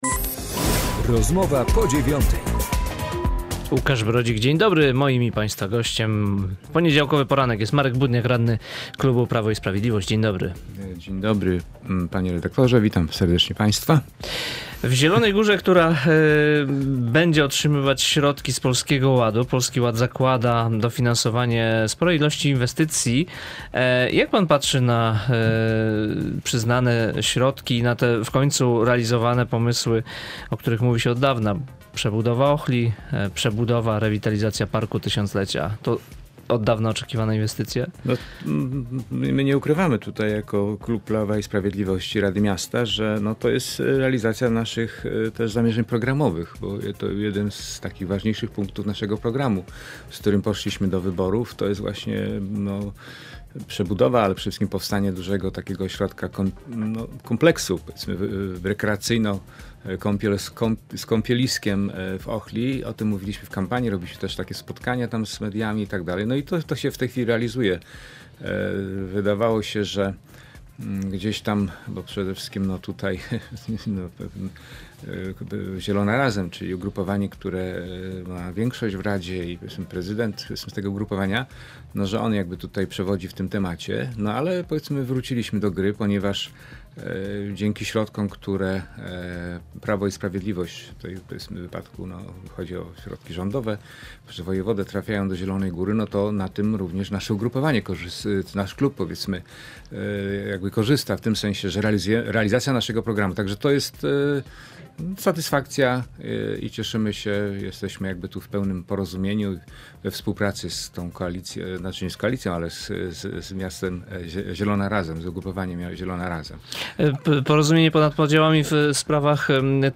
Z radnym klubu Prawo i Sprawiedliwość rozmawia